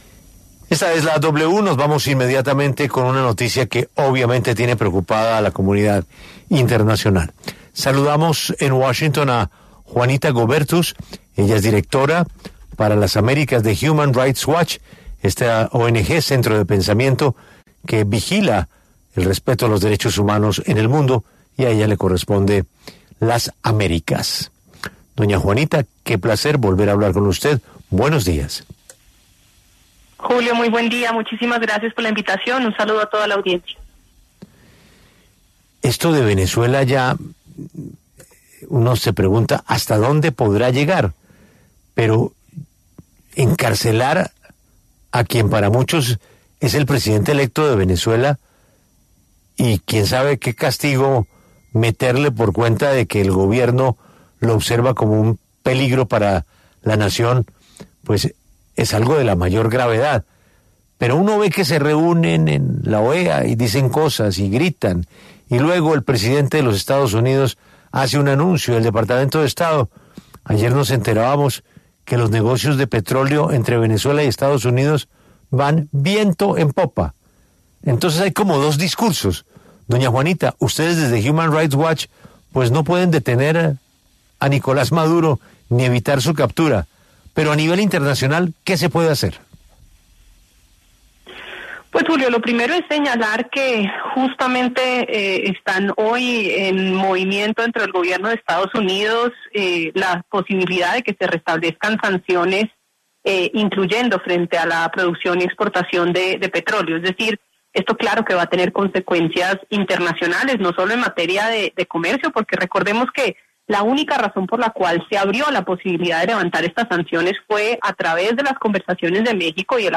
Juanita Goebertus, directora para la división de las Américas de Human Rights Watch, se refirió en La W al informe de la organización que denuncia una creciente represión en Venezuela.